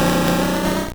Cri de Dardargnan dans Pokémon Or et Argent.